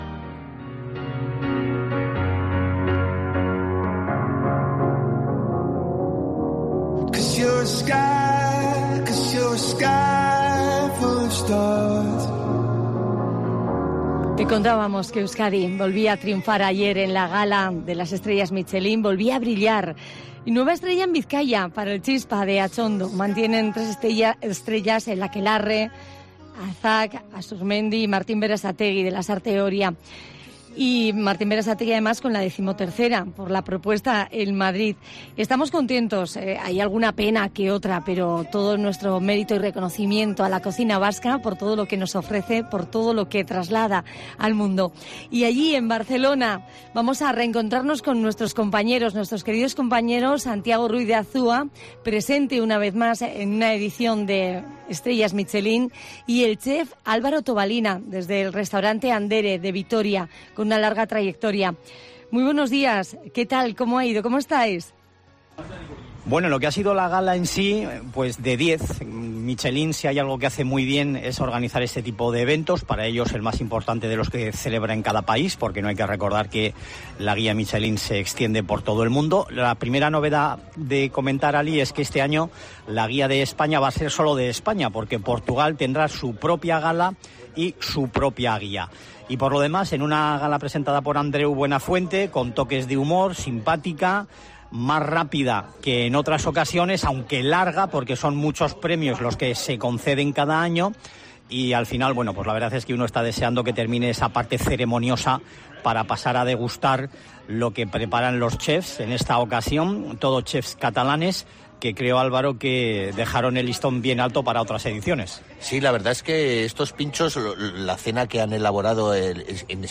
Desde Barcelona para COPE las Estrellas Michelín de Euskadi